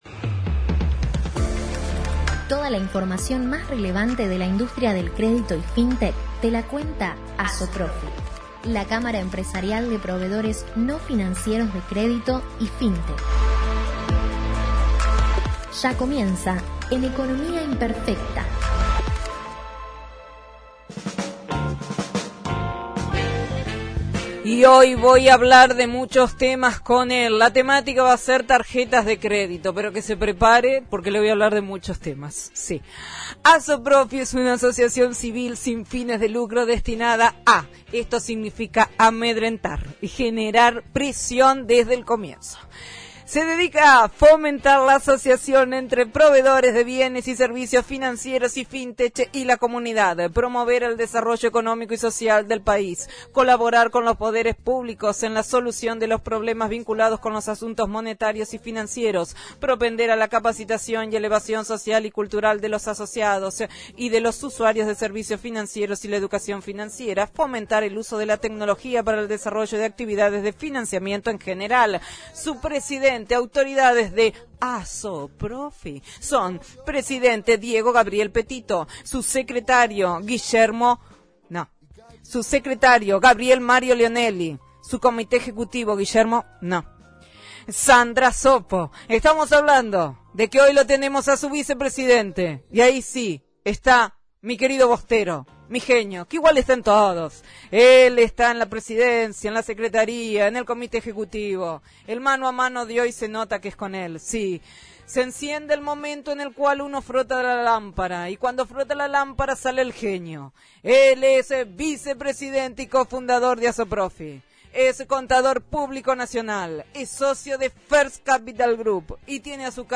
ASOPROFI – COLUMNA RADIAL – RADIO AM 1420 Miércoles 25/08/2021 – “Tarjetas de Crédito”